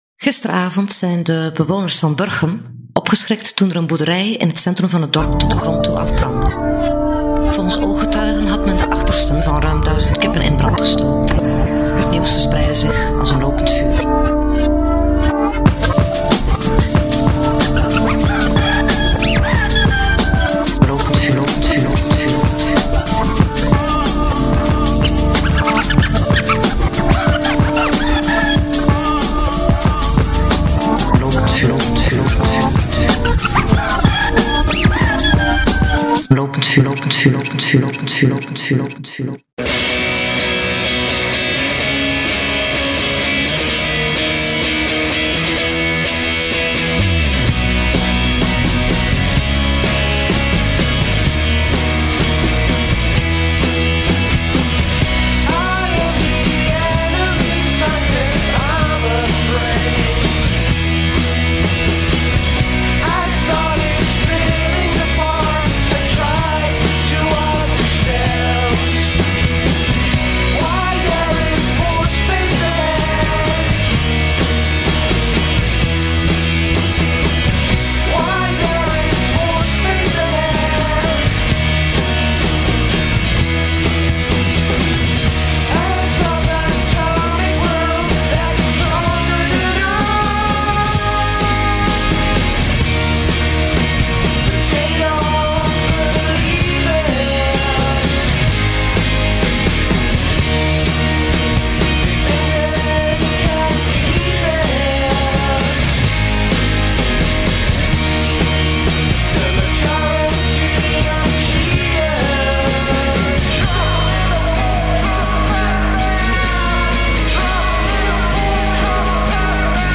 Hierin een interview